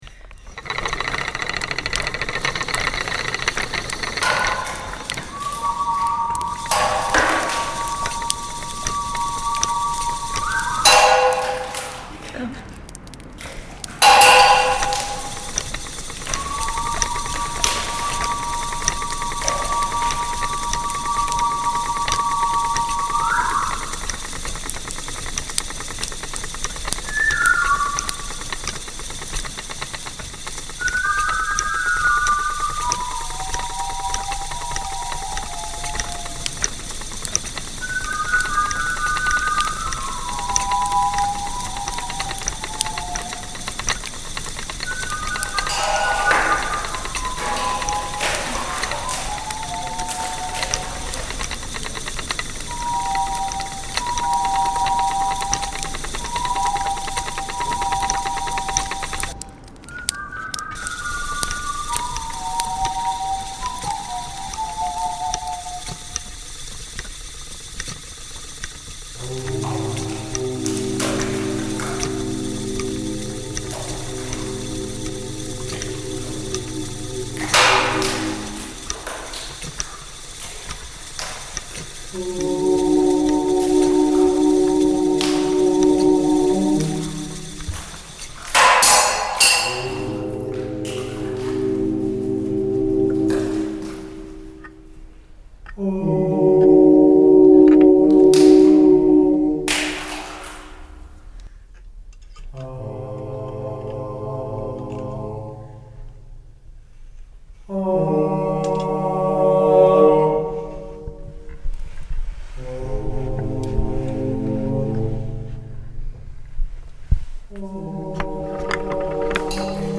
An acoustic exploration of our late eighteenth century Grade II listed ice house, built to serve the kitchens of Moseley Hall in an era before the invention of the refrigerator.
The tile features field recorded sounds based on workshops with pupils from Park Hill Primary School, and an interview